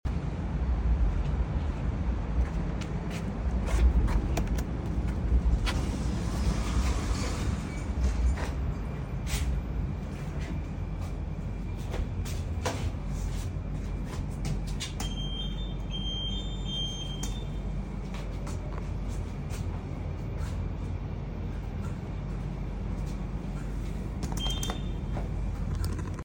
A Generic elevator lift at sound effects free download
A Generic elevator lift at John Cain Arena